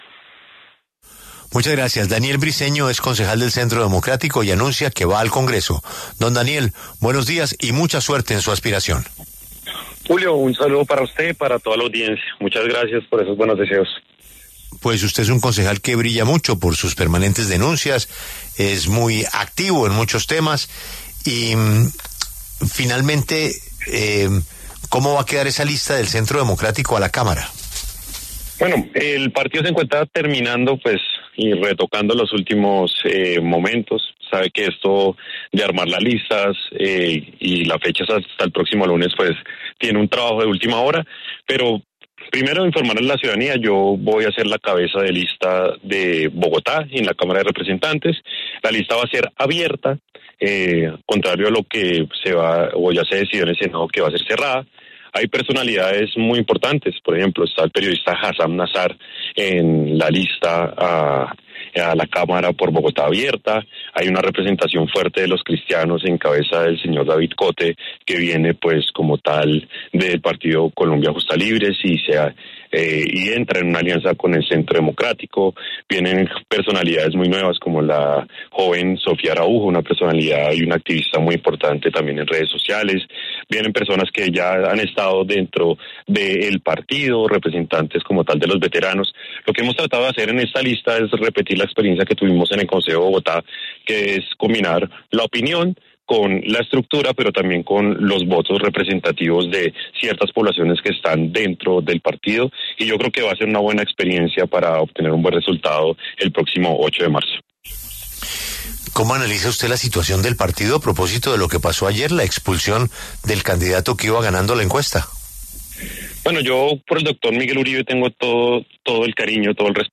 En los micrófonos de La W, el saliente concejal por el Centro Democrático, Daniel Briceño, habló sobre la crisis que vive el partido de oposición luego que decidieran expulsar a Miguel Uribe Londoño por su llamada a Abelardo de la Espriella para presuntamente adherirse a su campaña.